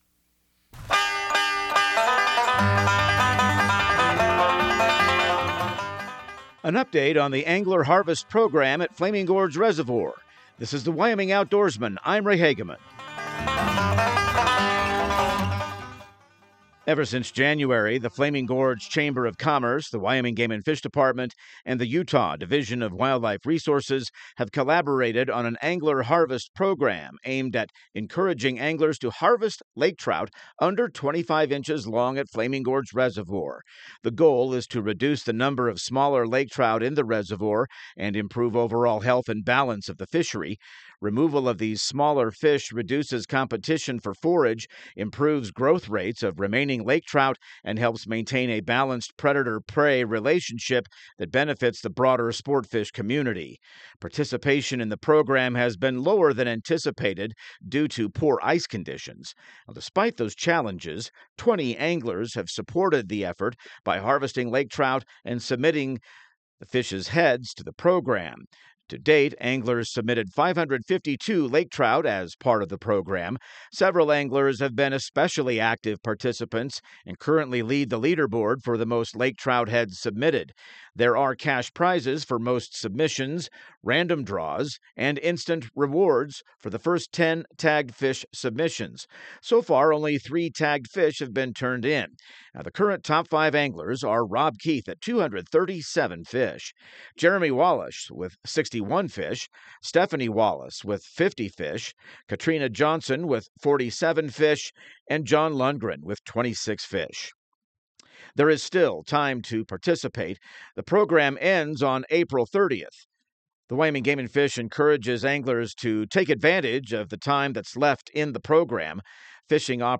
Radio news | Week of March 30